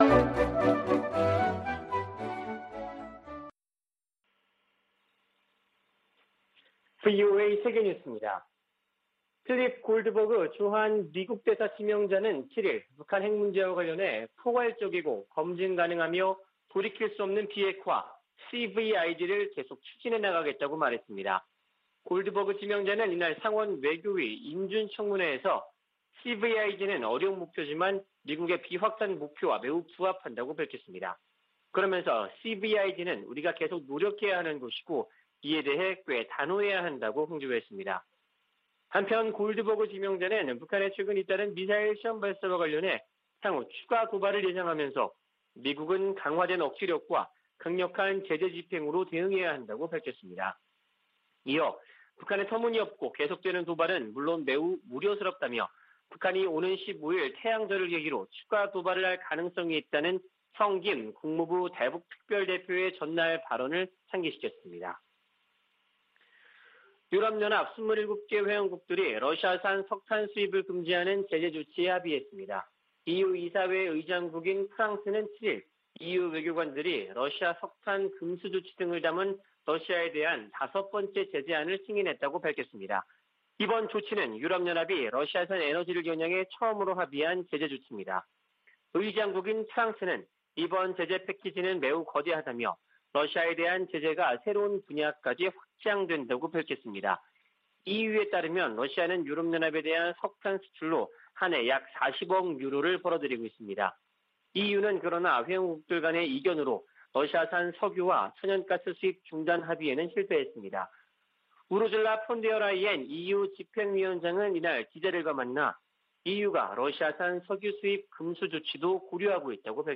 VOA 한국어 아침 뉴스 프로그램 '워싱턴 뉴스 광장' 2022년 4월 9일 방송입니다. 필립 골드버그 주한 미국대사 지명자는 북한의 완전하고 검증 가능하며 돌이킬 수 없는 비핵화(CVID)를 강력 추진해야 한다고 말했습니다.